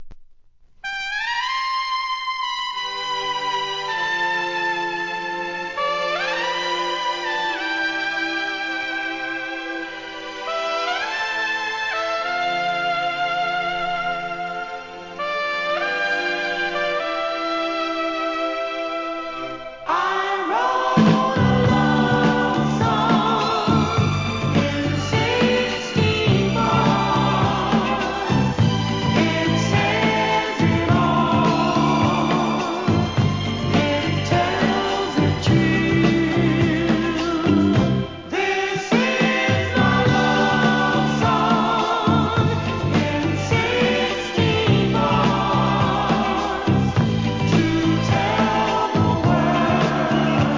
¥ 330 税込 関連カテゴリ SOUL/FUNK/etc...